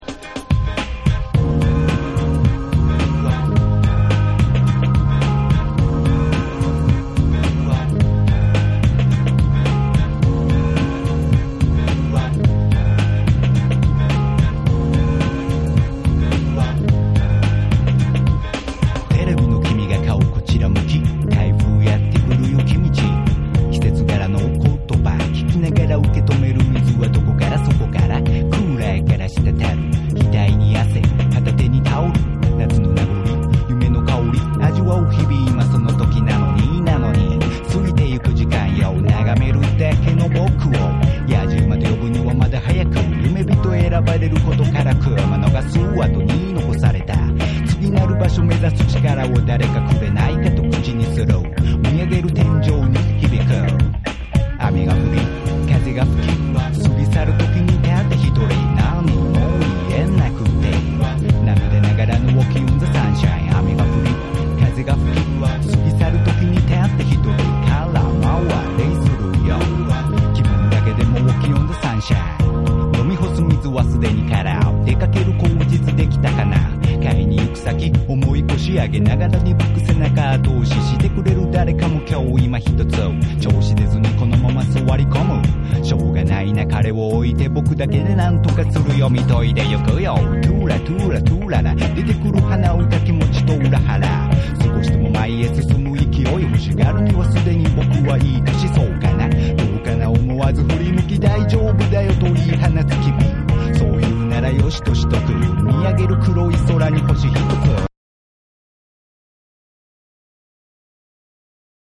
レイドバック感溢れるメロウなトラックと、ポエトリー調のラップが一体となり展開する